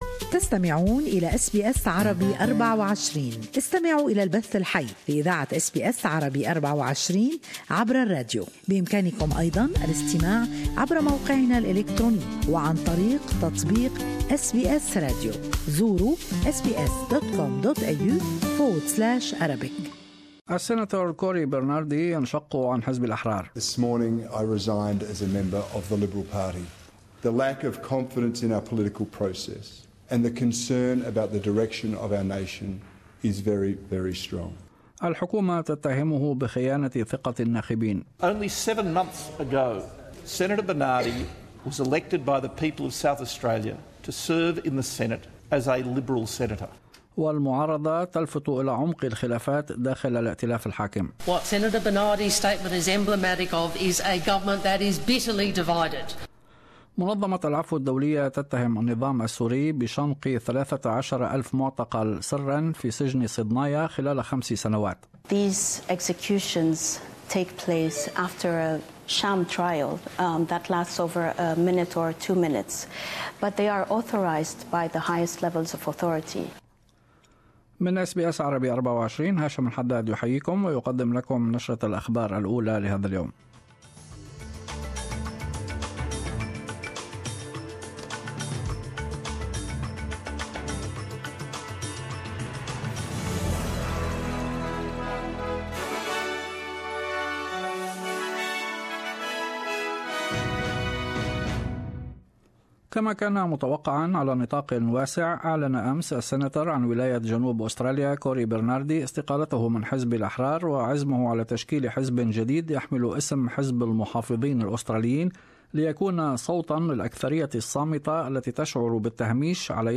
News Bulletin 8-2-17